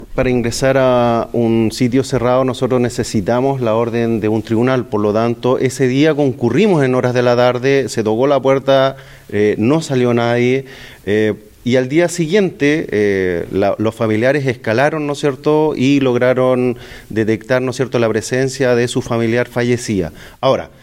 Ante ello, el general Patricio Yáñez, jefe de la IX Zona de Carabineros, dijo que el jueves a eso de las 18:00 horas recibieron la denuncia por presunta desgracia y que el personal policial llegó hasta el departamento de la torre Génova, pero que no ingresaron porque no había orden judicial.